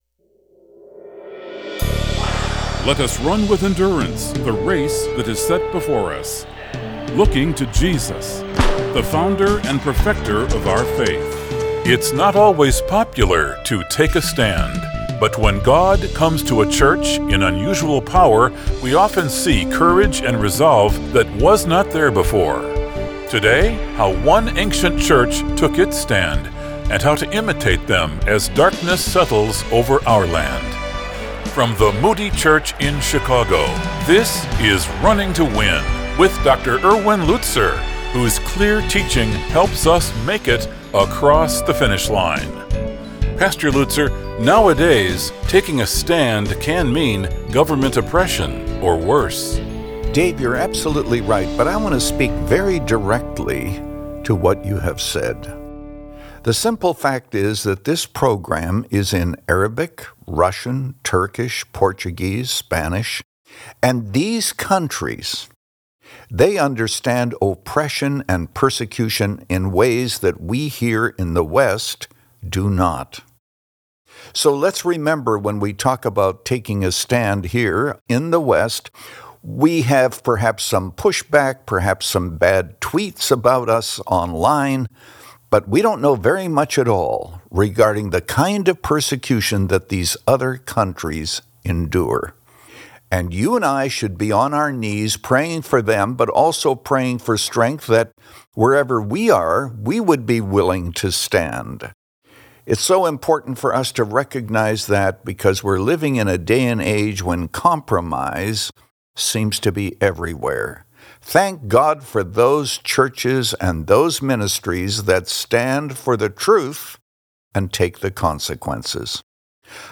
But when God comes in concentrated power to a church, we often see courage and resolve that were not there before. In this message from 1 Thessalonians 3, Pastor Lutzer shares Paul’s strategy to stand against temptation—together.
But with the Bible front and center and a heart to encourage, Pastor Erwin Lutzer presents clear Bible teaching, helping you make it across the finish line. Since 2011, this 25-minute program has provided a Godward focus and features listeners’ questions.